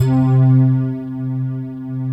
Index of /90_sSampleCDs/Big Fish Audio - Synth City/CD1/Partition B/05-SYNTHLEAD